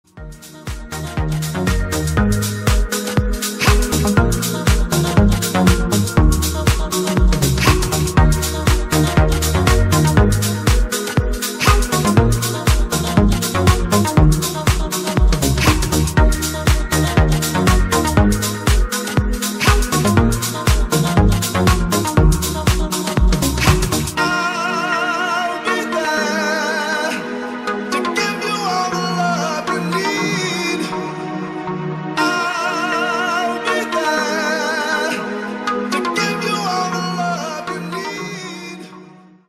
• Качество: 160, Stereo
deep house
красивый мужской голос
Electronic
красивая мелодия